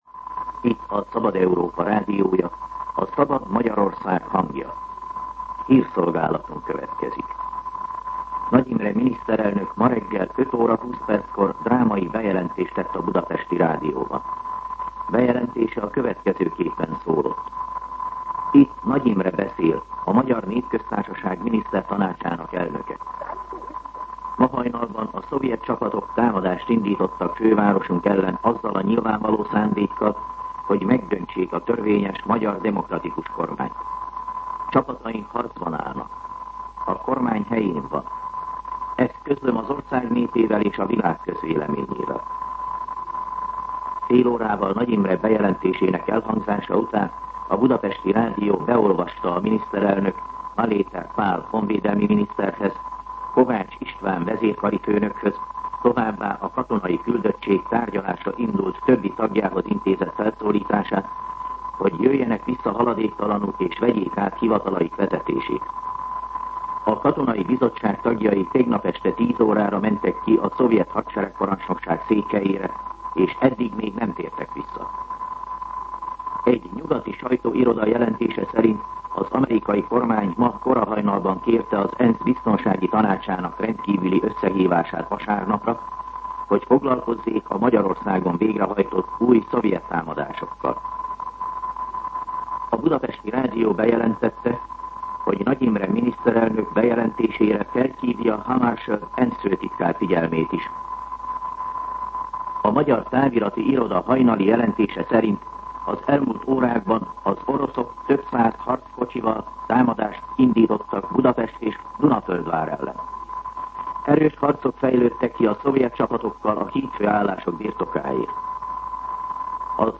MűsorkategóriaHírszolgálat